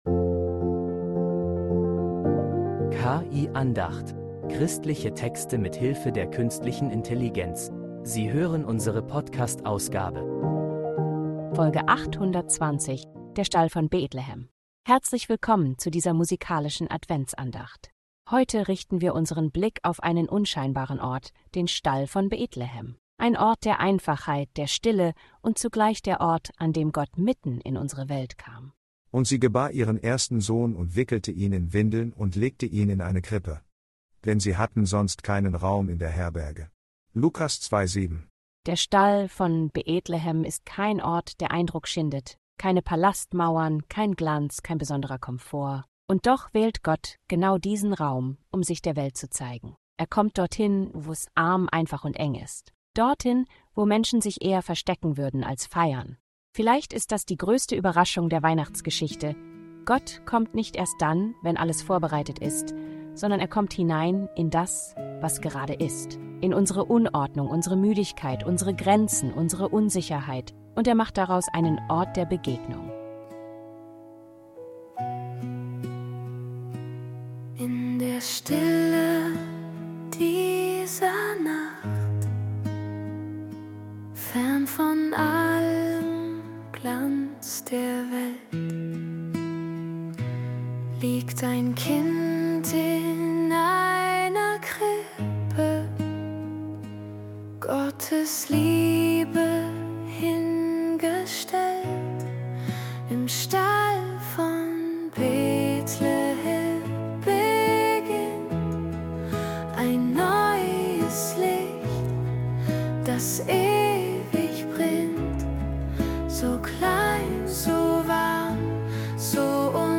Christliche Texte mit Hilfe der Künstlichen Intelligenz